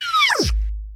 sfx_shoot.wav